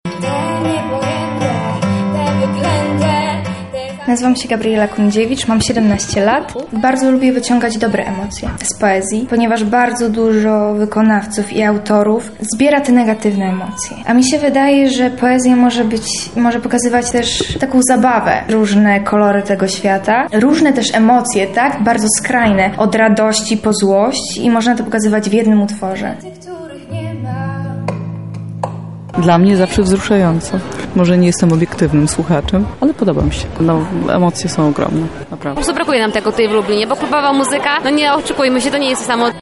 Lublin po raz kolejny wsłuchał się w poezję śpiewaną. Za nami trzecia edycja festiwalu „Dźwięki Słów”